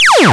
Шаг 16. Добавим звук выстрела
Ты можешь скачать звуки, используемые в этом туториале, здесь: 🔊 LaserSound.wav — проигрывается при выстреле ракеты или лазера 💥 Boom.wav — проигрывается при взрывах (враг, игрок, попадание лазера) Импортируй скачанные аудиофайлы в Unity.
LaserSound.wav